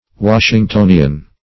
Washingtonian \Wash`ing*to"ni*an\, a.